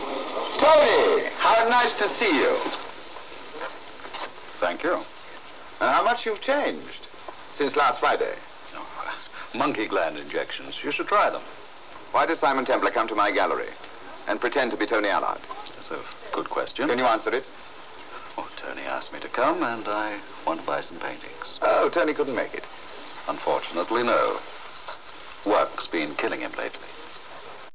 Admiralble Wit we all Love about Roger... (Episode: "The Man Who Loved Lions")